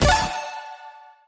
Win_Eliminate_Sound.mp3